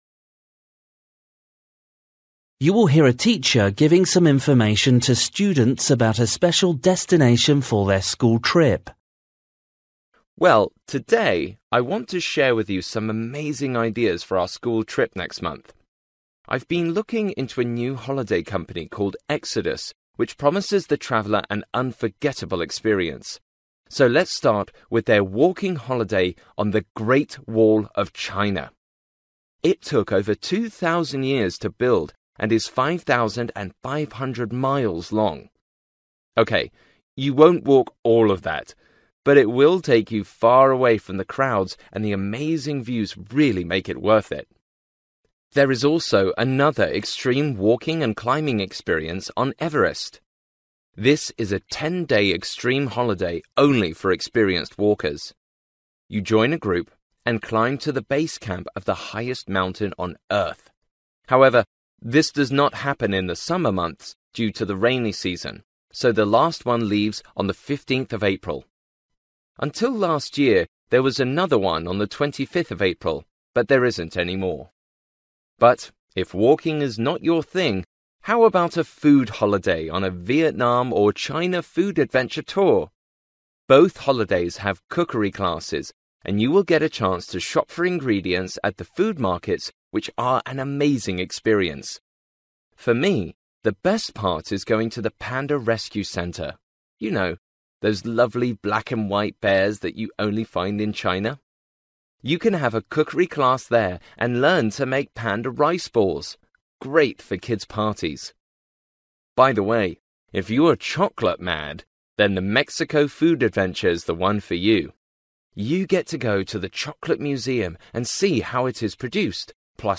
You will hear a teacher giving some information to students about a special destination for their school trip.